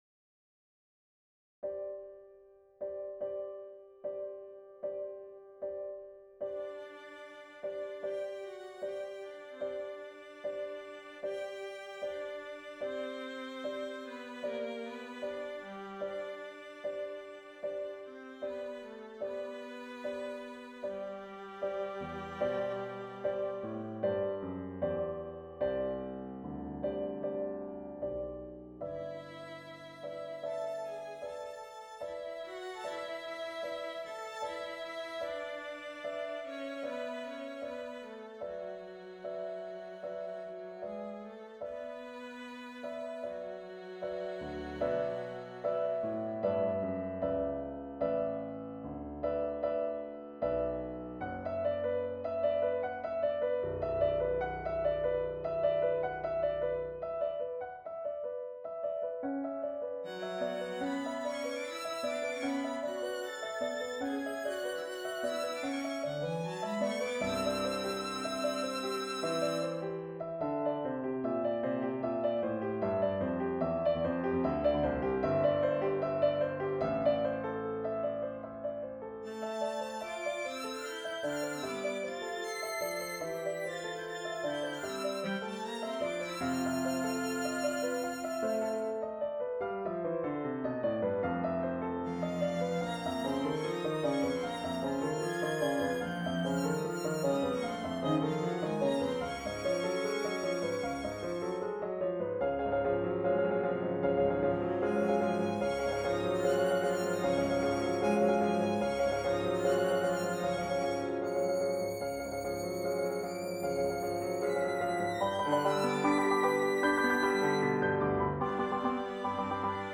Here it comes: Does this music for violin, cello and piano strike you as superficial?
I guess my problem with the piece is that the harmonic progression is quite unusual and it feels a bit random.
I think your piano trio is an exercise in constant variation while still staying related to the beginning.  It's cool though that it manages to return to the original theme towards the end.  I'd say it doesn't however have a very conclusive ending - it just stops.
It develops mostly through rhythmic diminution and harmonic changes making it sound more exciting and full of motion.  I guess I feel like the dynamics in the piece are a bit flat and could stand to grow more.